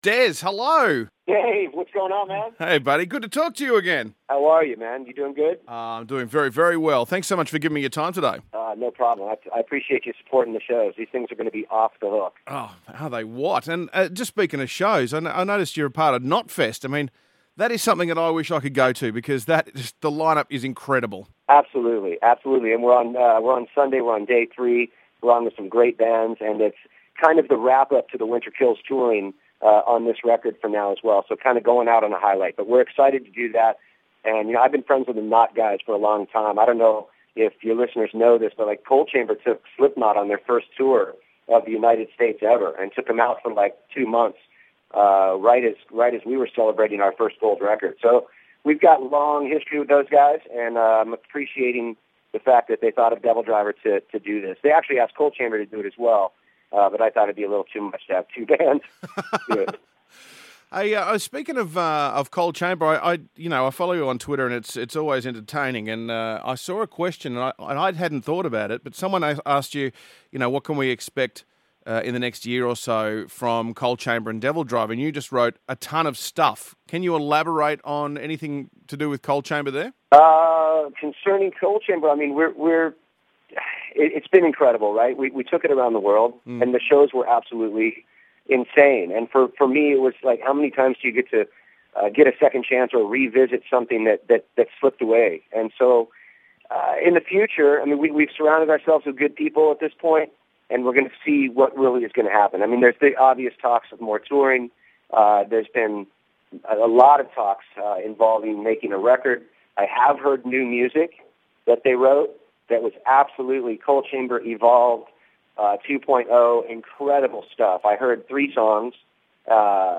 Dez Fafara interview